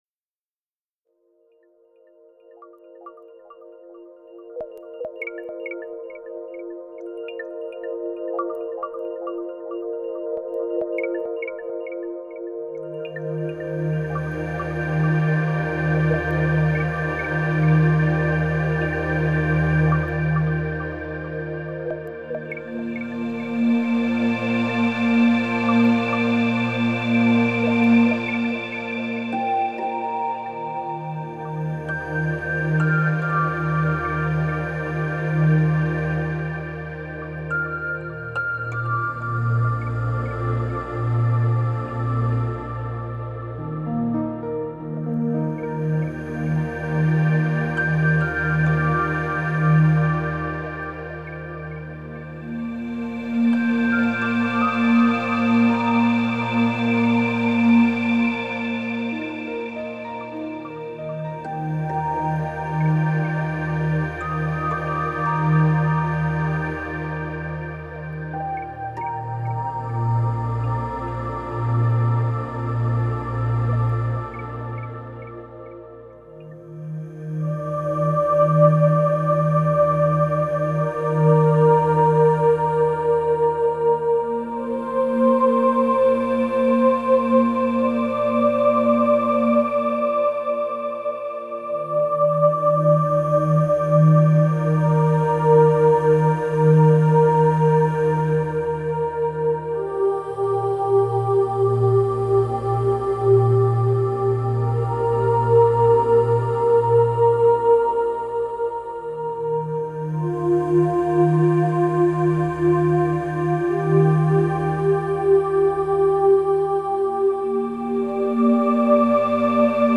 「YOGA NIDRA（ヨーガ ニードラ）」瞑想用CDです。